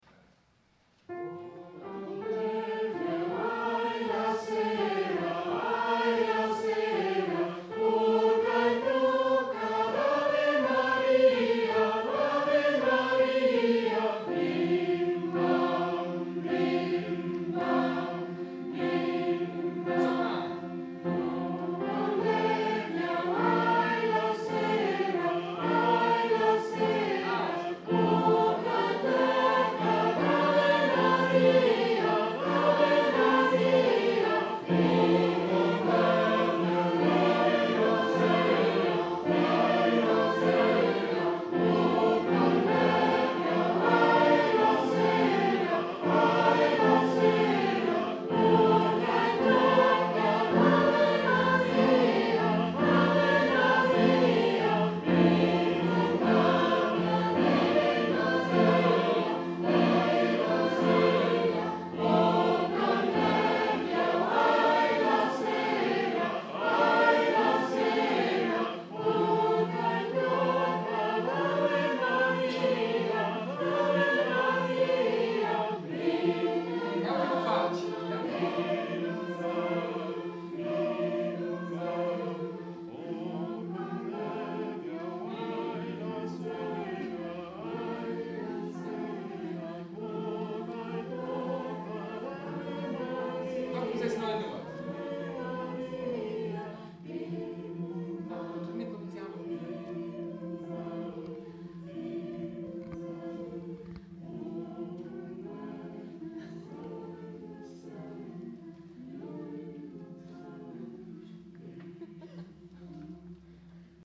Participants learn a Romansh song